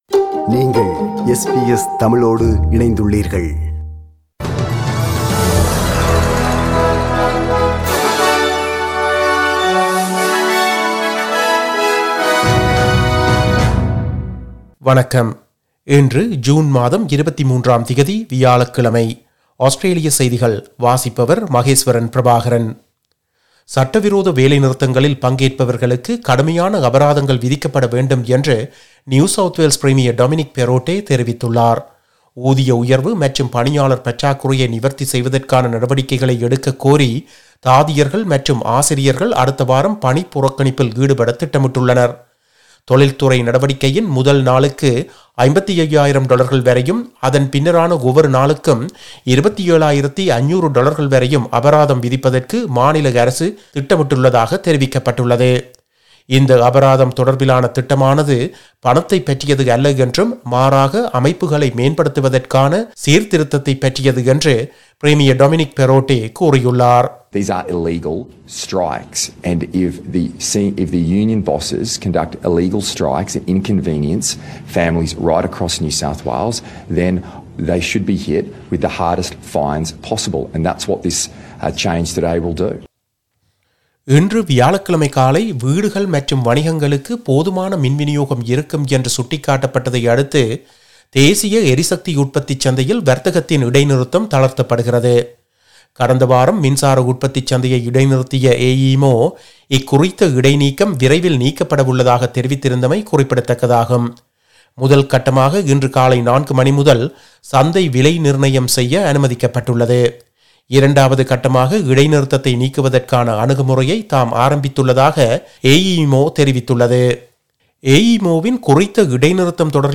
Australian news bulletin for Thursday 23 June 2022.